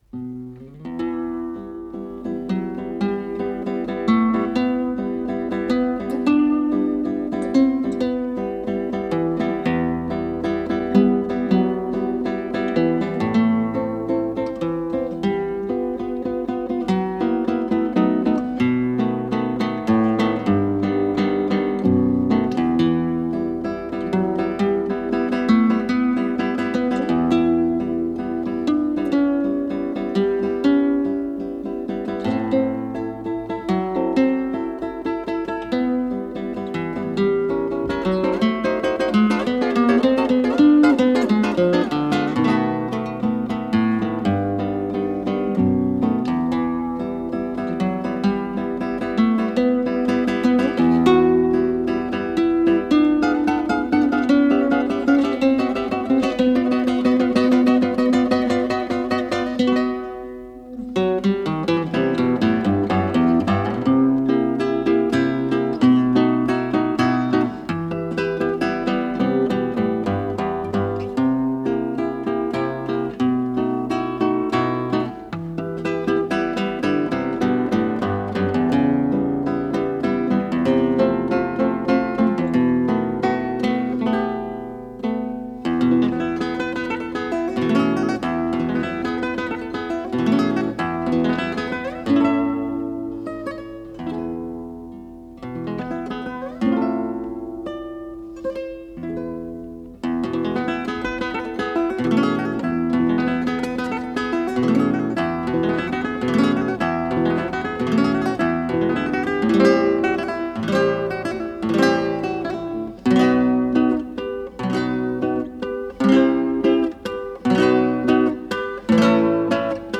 с профессиональной магнитной ленты
гитара